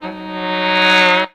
SHORT SWL 2.wav